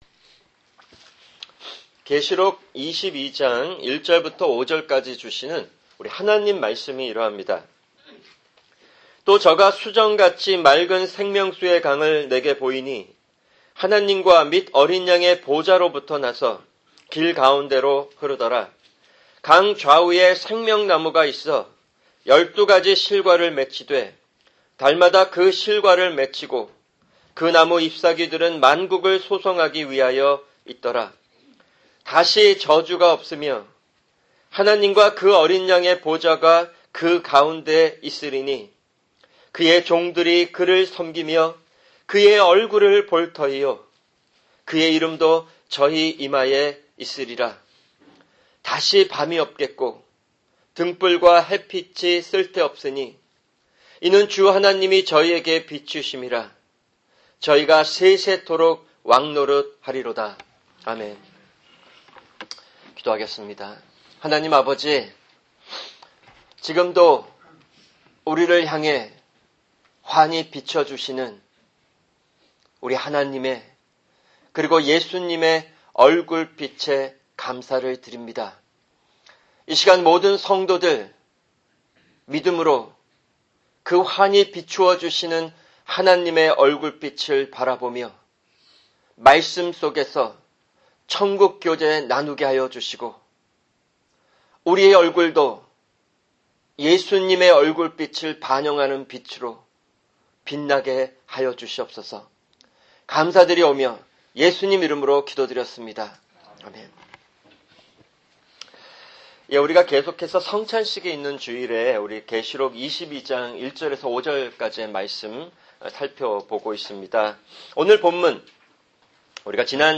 [주일 설교] 계시록22:1-5(2) – 성찬